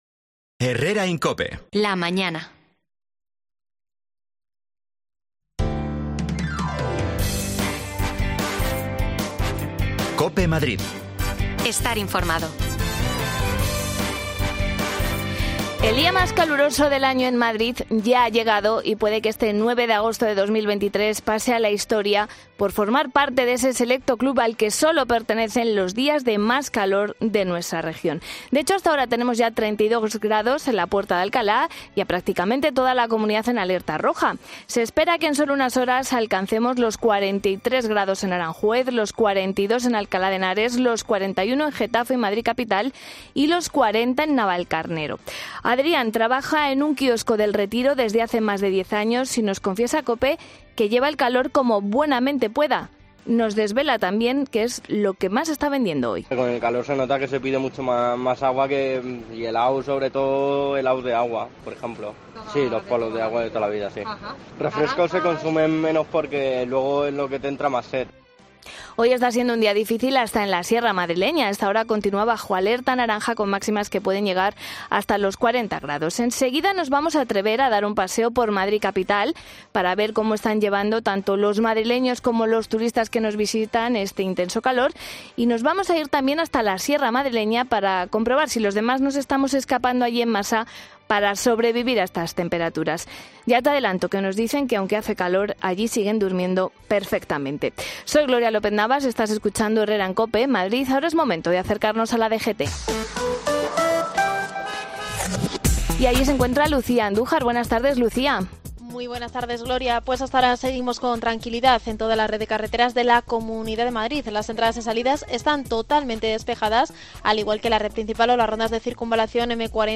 Nos damos un paseo por el centro de la capital y por la sierra madrileña para comprobarlo.
Las desconexiones locales de Madrid son espacios de 10 minutos de duración que se emiten en COPE, de lunes a viernes.